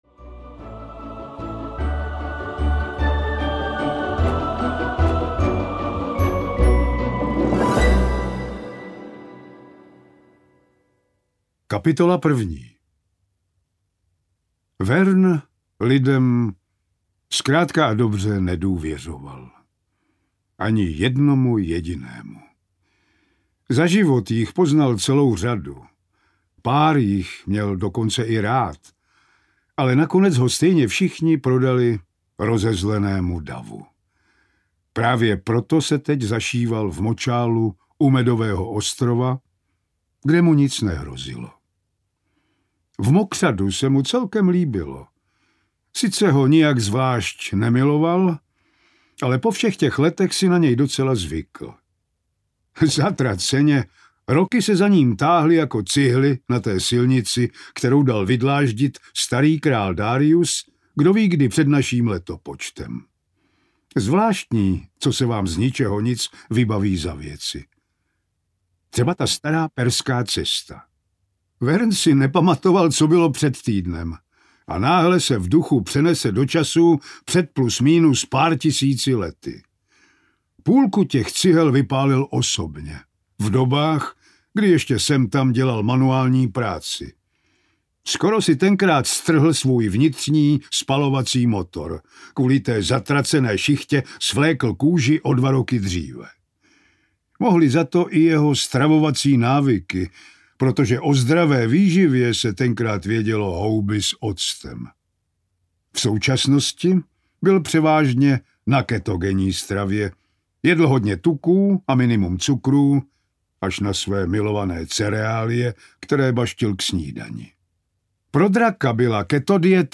Arcižár audiokniha
Ukázka z knihy
• InterpretPavel Rímský
arcizar-audiokniha